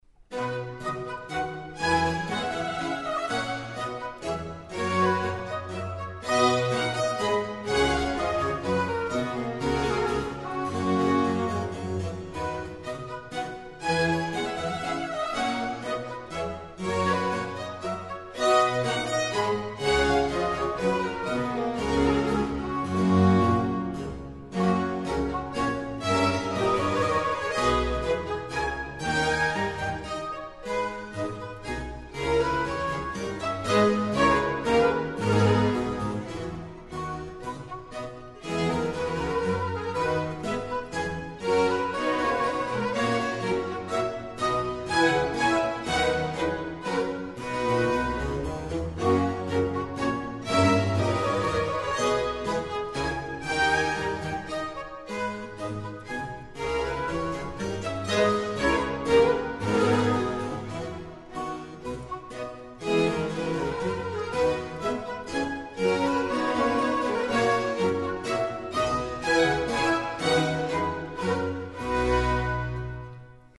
Als Beispiel für ein Menuett ein Satz aus der Ouvertüre C-Dur von Johann Sebastian Bach (entstanden um 1725)
menuett.mp3